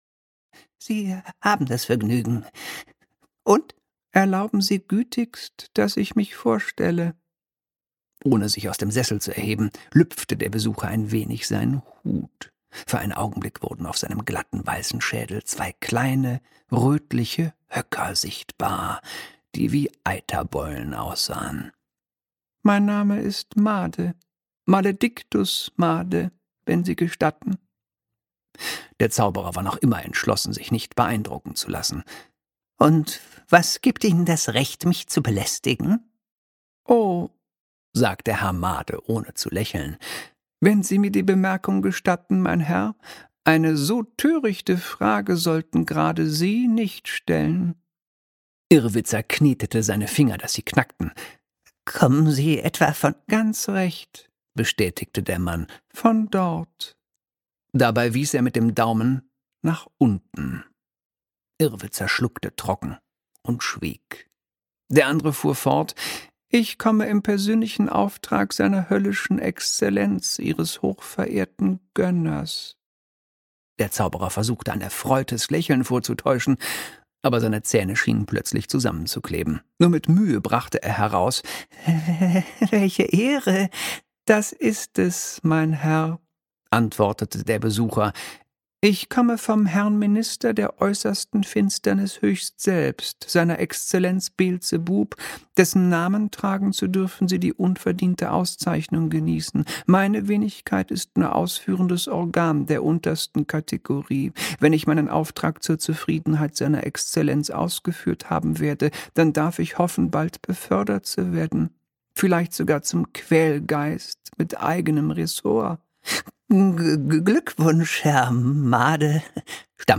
Der satanarchäolügenialkohöllische Wunschpunsch - Die Lesung - Michael Ende - Hörbuch